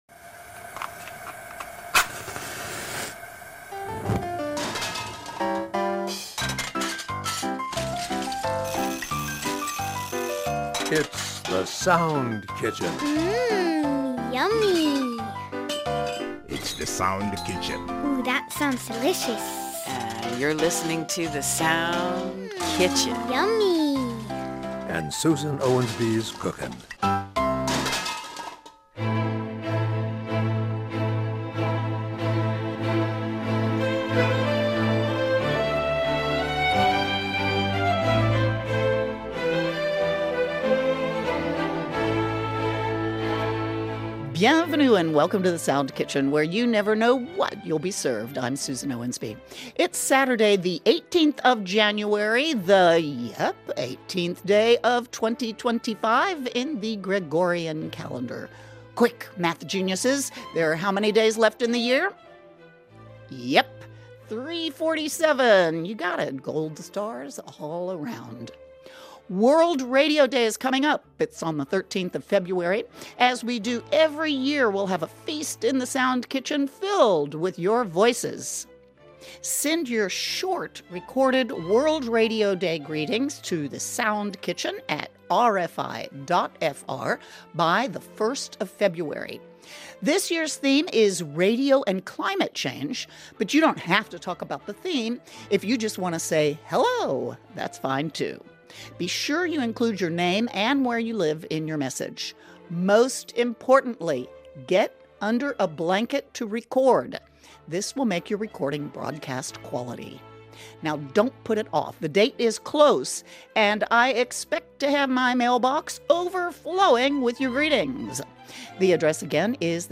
Music, interviews, quirky listener essays ...